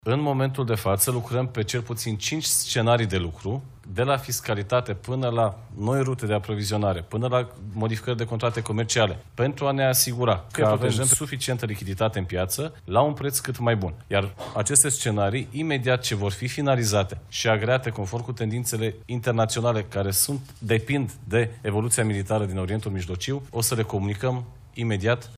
Ministrul Energiei, Bogdan Ivan: „Lucrăm la cel puțin cinci scenarii de lucru”